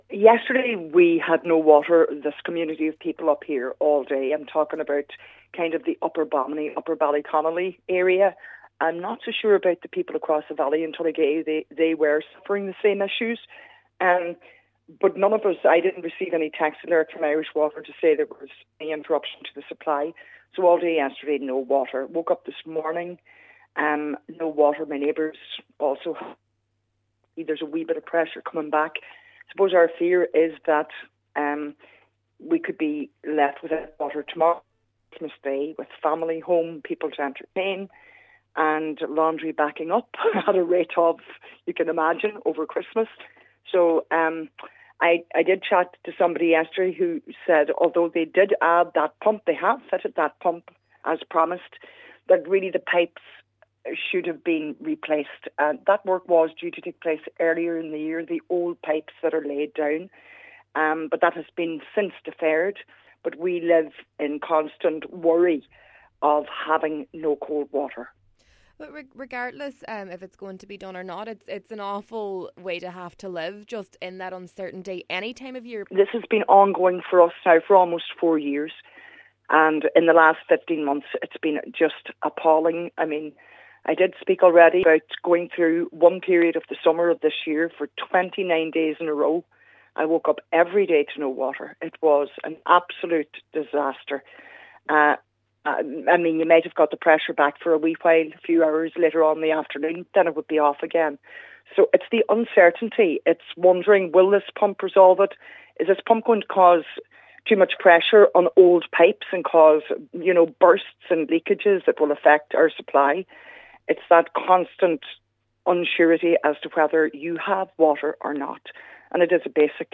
A woman who lived in the area says it has been a long four years for the people in the area.